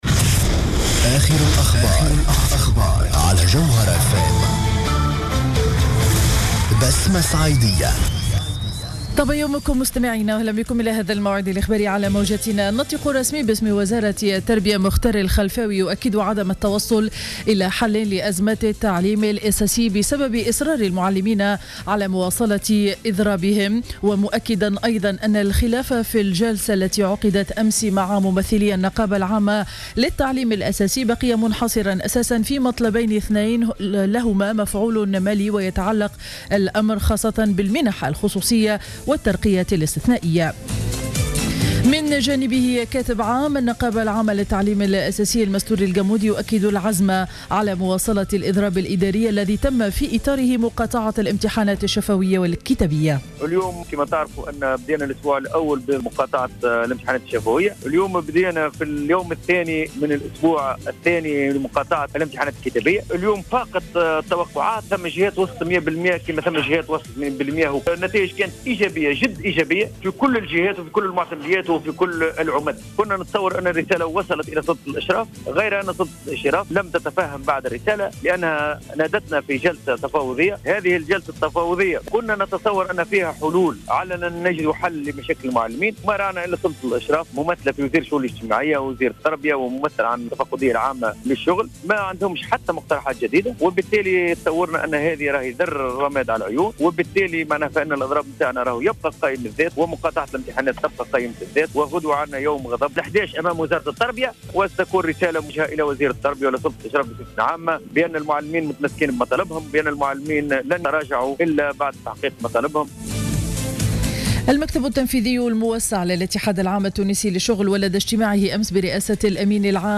نشرة أخبار السابعة صباحا ليوم الإربعاء 10 جوان 2015